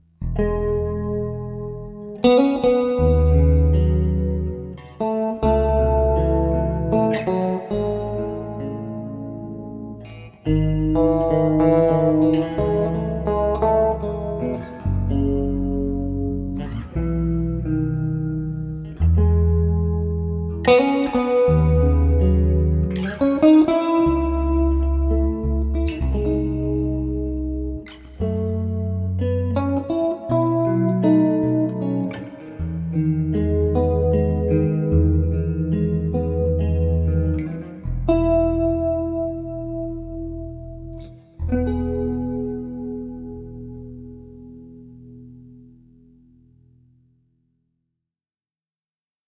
Bass VI
Elle est accordée comme une guitare mais à l’ octave inférieure.
Ici 2 extraits , un au médiator et l’ autre en arpège.